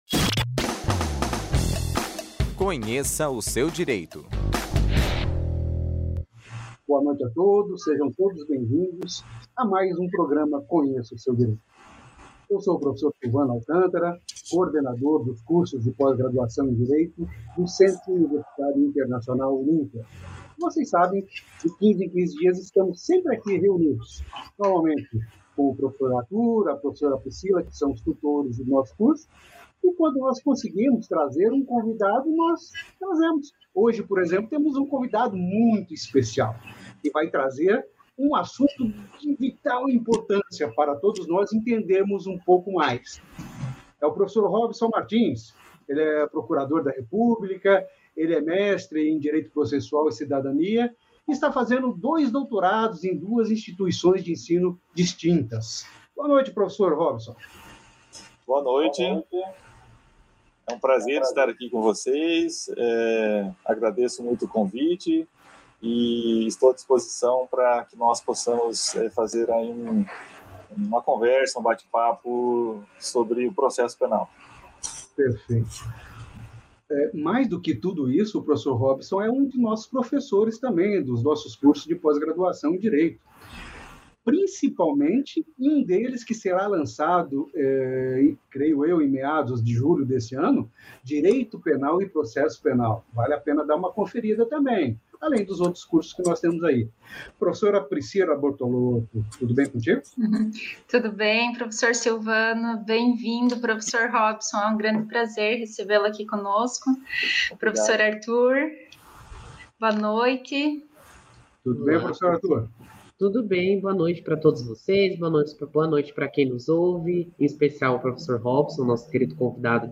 Para falar sobre o assunto recebemos o Procurador da República, Robson Martins.